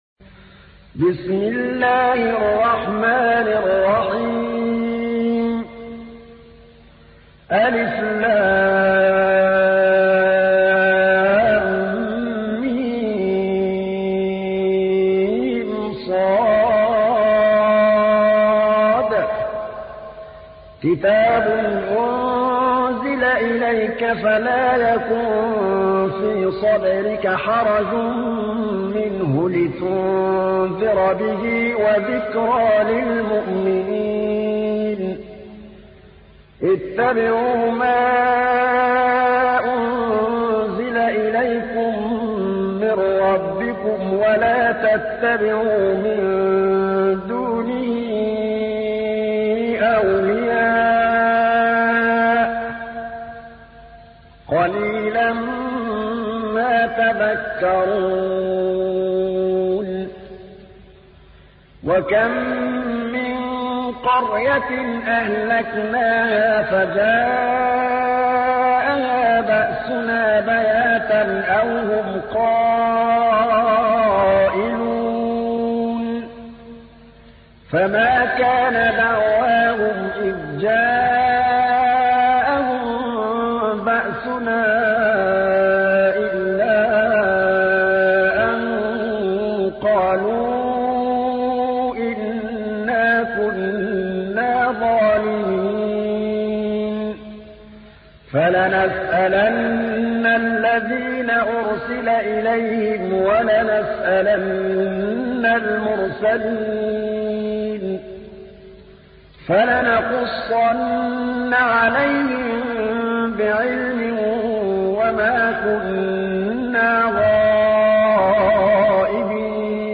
تحميل : 7. سورة الأعراف / القارئ محمود الطبلاوي / القرآن الكريم / موقع يا حسين